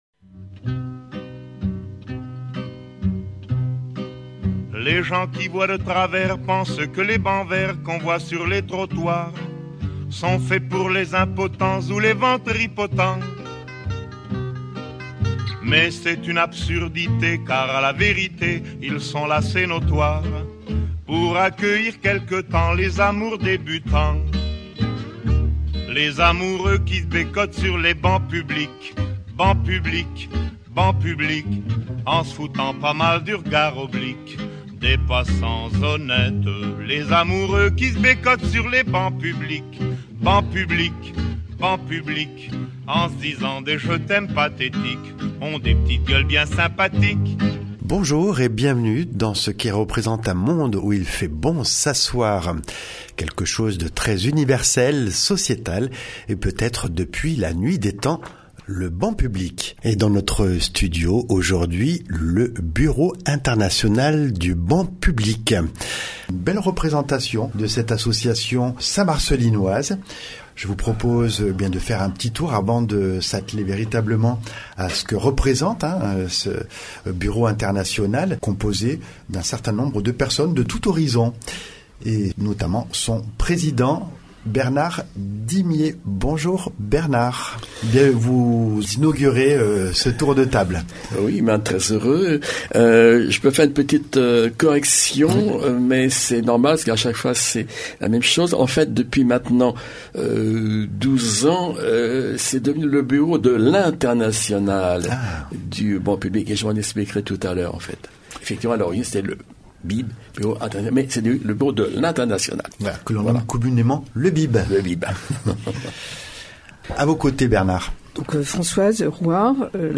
Enregistré le 26 février 2025 dans le studio de Radio Royans.